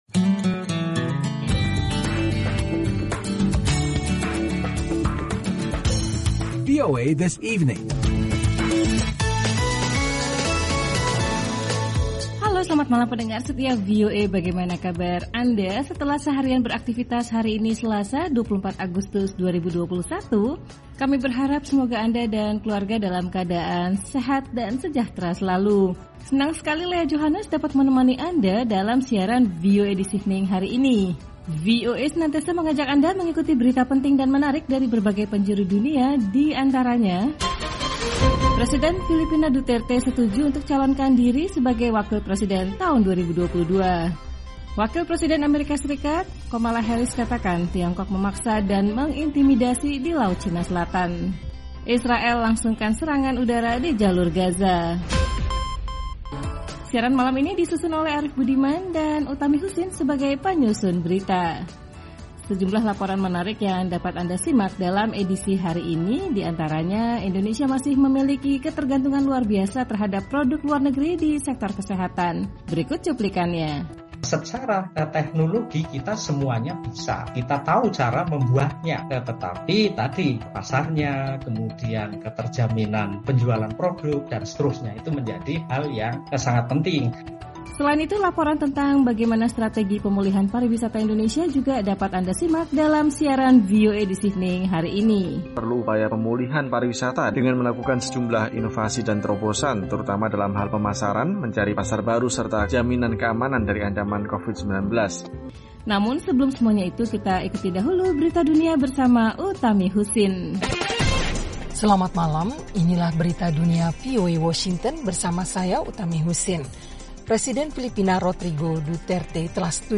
Siaran VOA This Evening 24 Agustus 2021